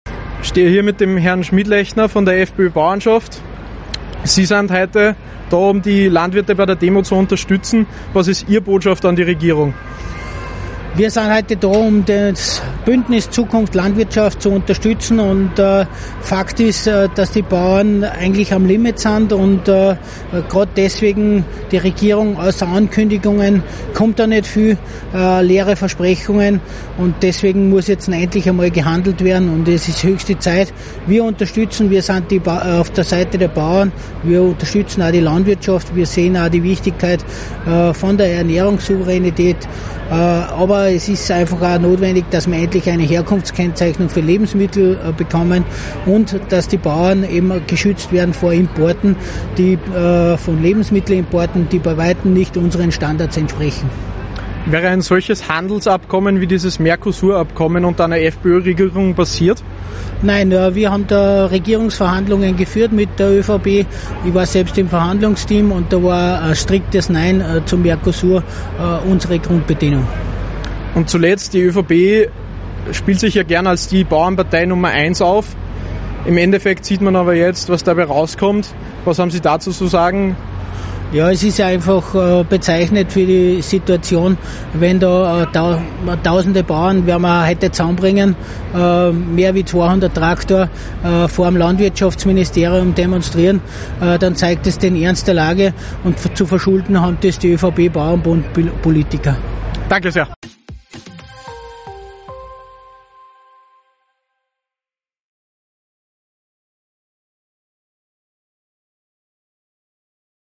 Bauerndemo in Wien vor Ort unterstützt. Im Interview mit AUF1